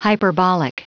Prononciation du mot hyperbolic en anglais (fichier audio)
Prononciation du mot : hyperbolic